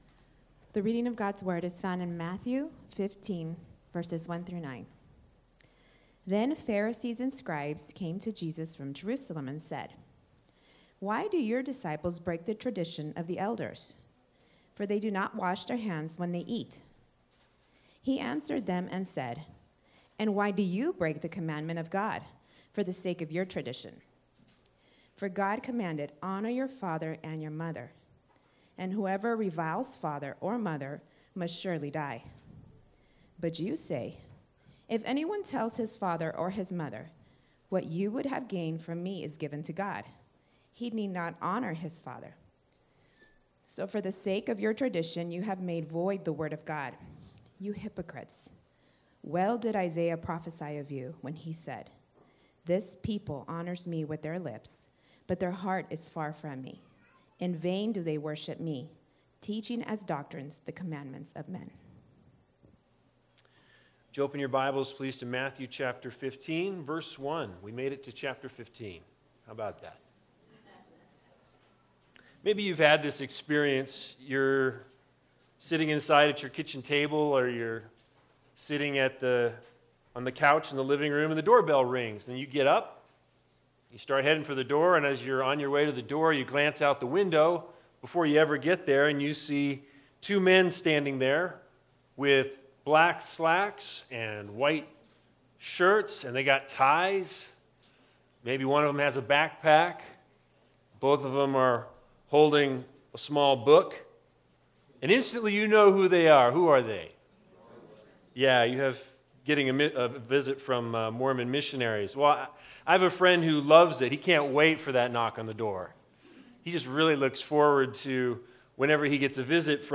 Matthew 15:1-9 Service Type: Sunday Sermons The Big Idea